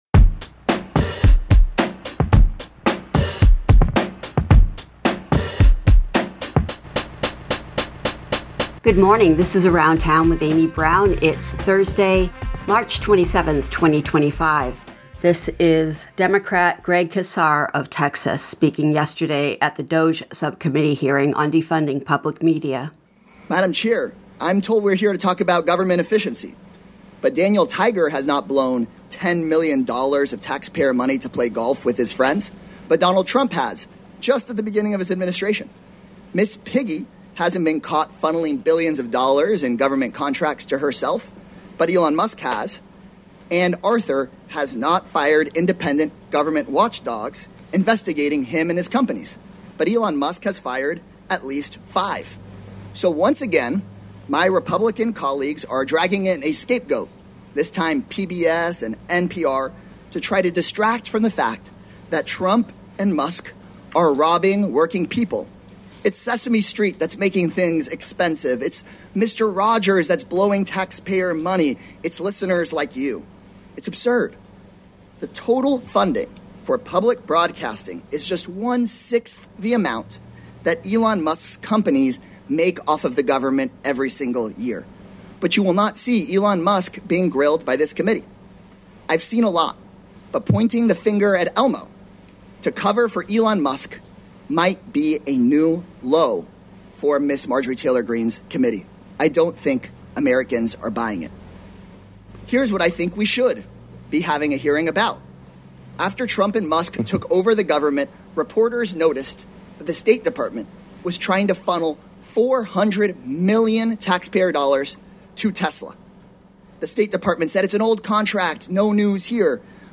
Congressman Greg Caser defending public media (and questioning other spending) at the Congressional Subcommittee on Delivering on Government Efficiency (DOGE) meeting on defunding public media yesterday